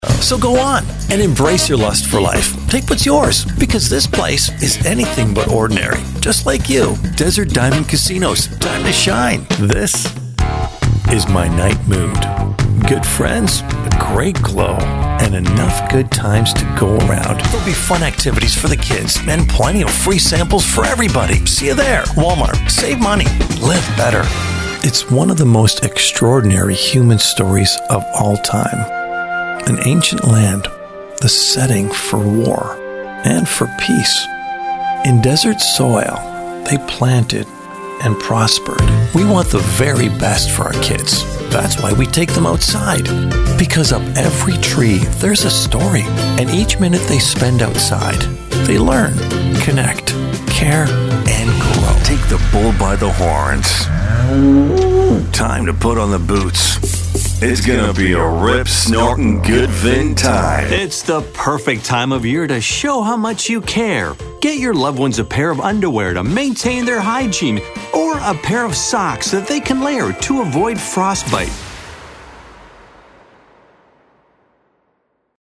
Inglês (canadense)
Demonstração Comercial
Meia-idade
ÚnicoEsquentarAmigáveisHonesta